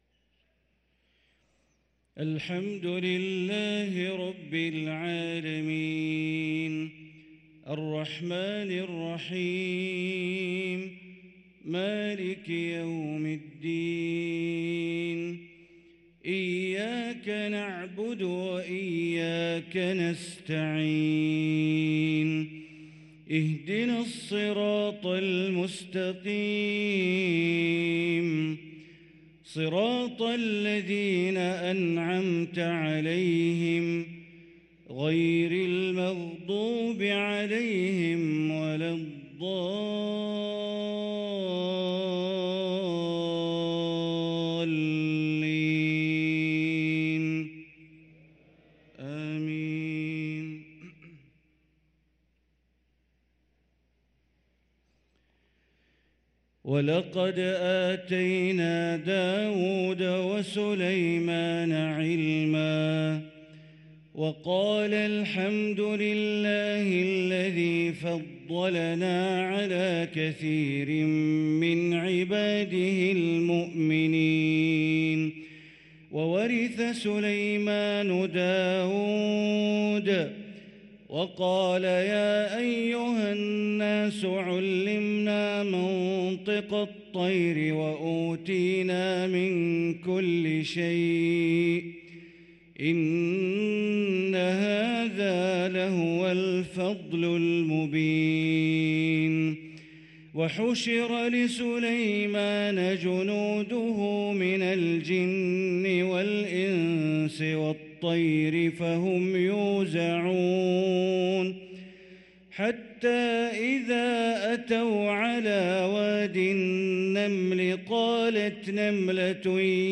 صلاة العشاء للقارئ بندر بليلة 28 جمادي الآخر 1444 هـ